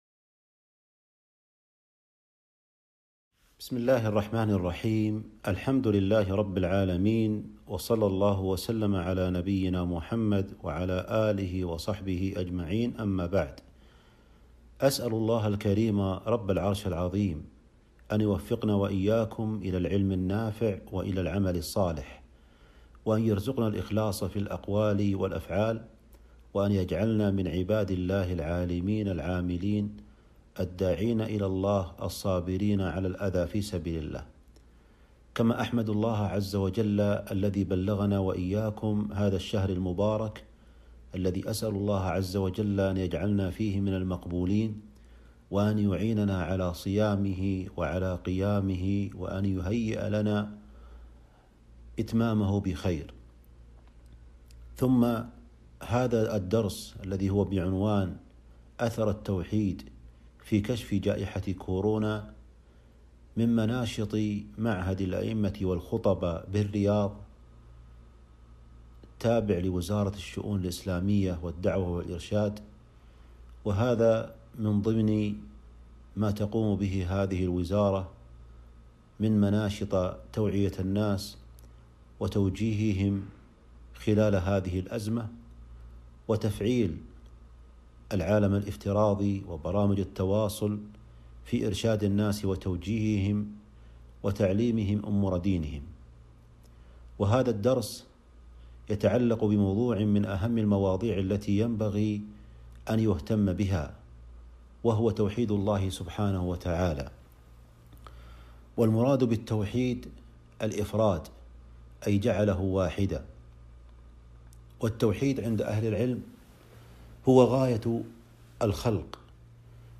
أثر التوحيد في كشف جائحة كورونا المحاضرة الأولى